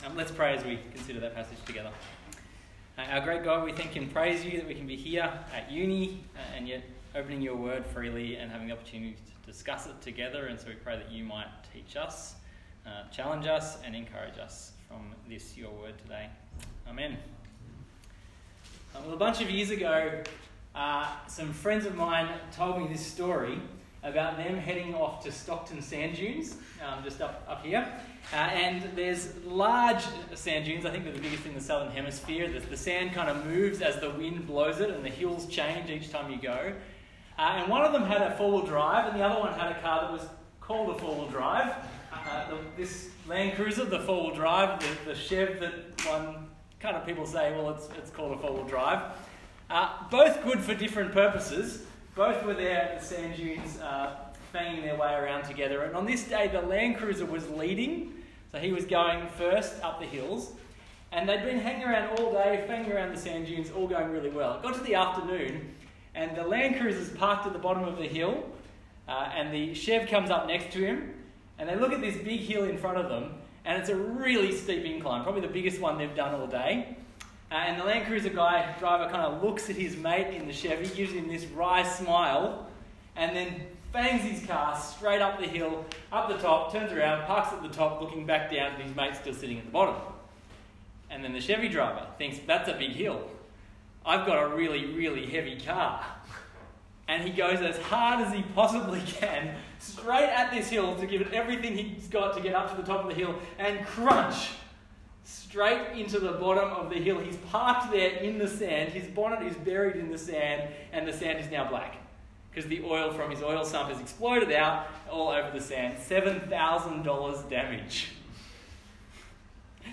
Passage: Romans 14:1-15:33 Talk Type: Bible Talk